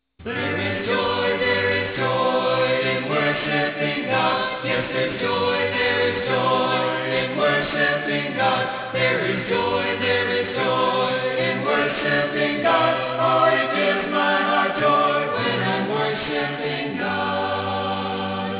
Soprano
Altos
Tenor
Bass